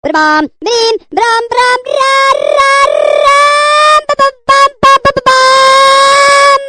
crazy-frog-stuttering_21693.mp3